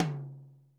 RX5 TOM 1.wav